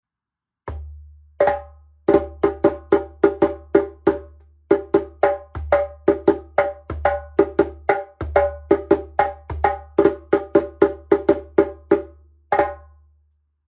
おそらく2000年代前半のギニアジェンベ、分解してボディ調整からリング作成までフルレストアしてみました。
持ち運びも楽チンな重さ6キロ台、それでいて叩きやすい32.5センチ口径なので音のレンジも広く、３音出しやすいです。